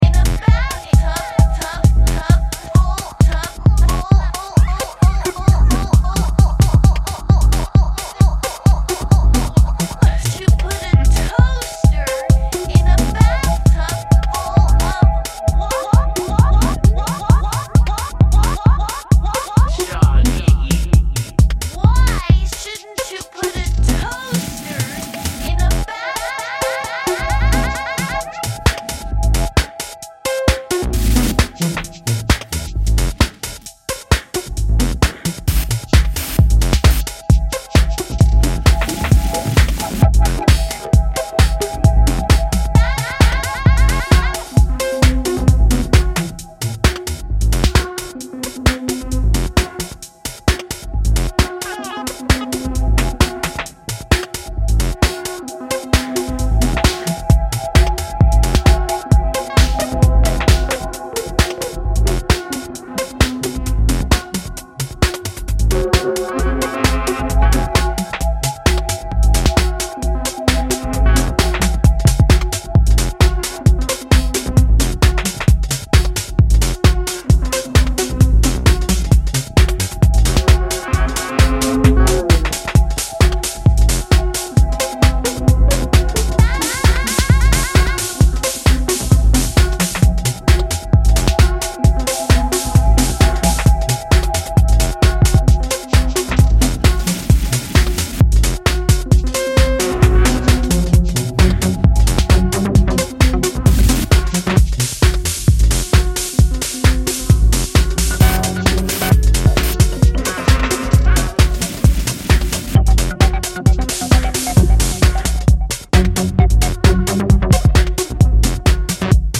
quirky and twisted minimal throbber